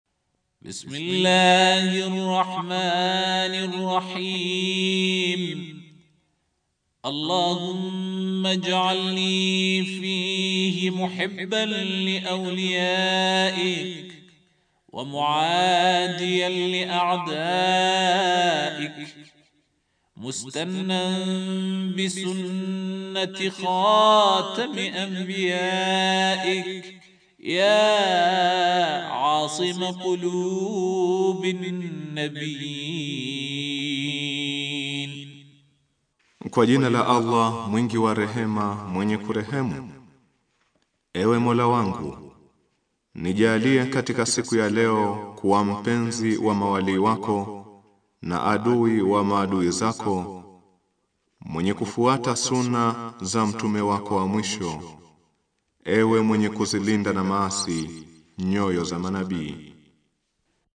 Dua ya siku ya ishirini na tano ya Ramadhani